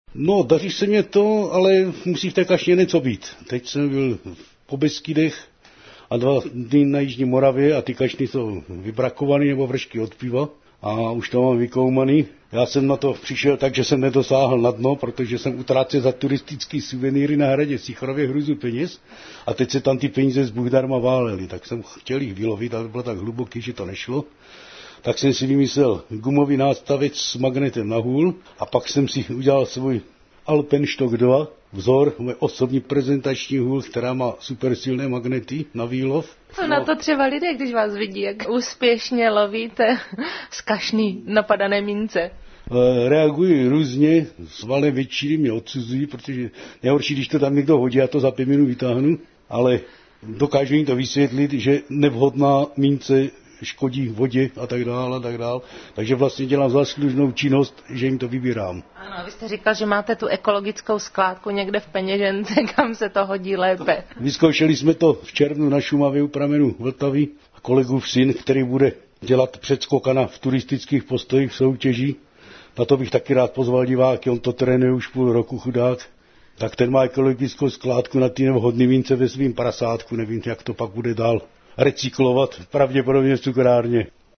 ZVUKOV� POZV�NKA Z �ESK�HO ROZHLASU OLOMOUC